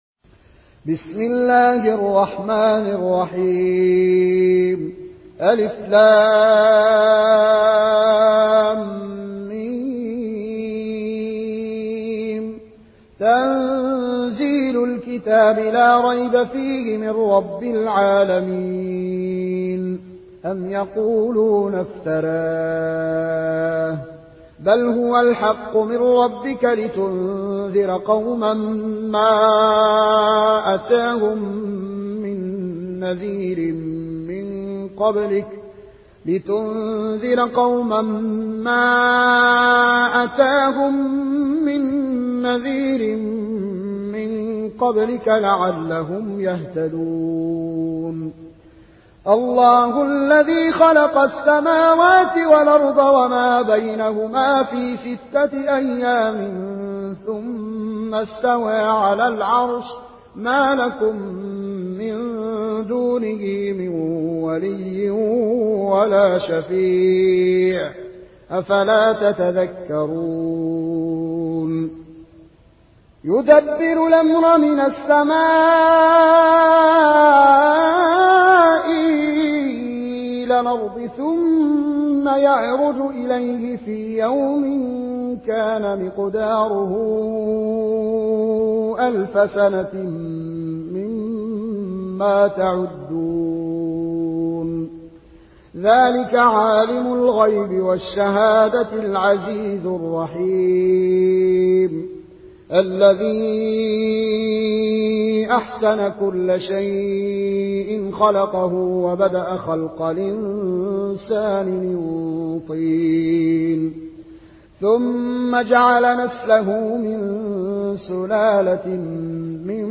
উপন্যাস Warsh
Warsh থেকে Nafi